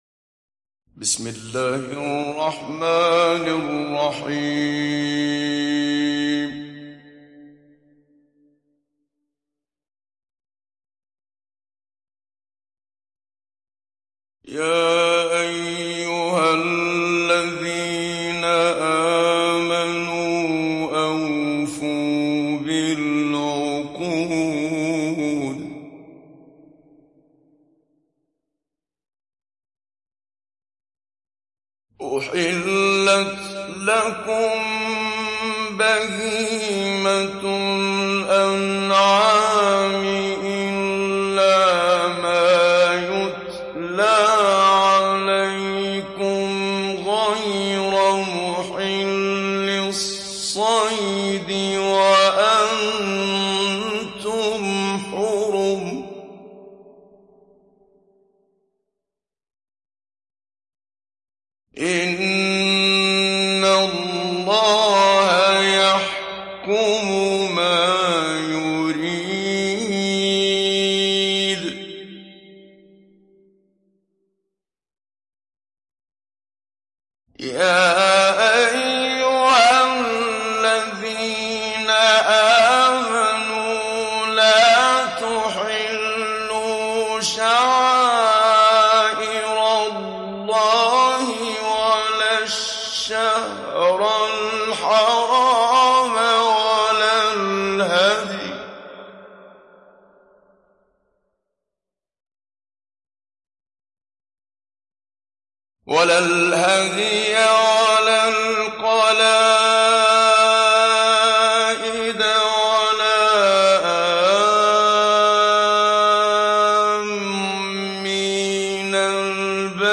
Download Surah Al Maidah Muhammad Siddiq Minshawi Mujawwad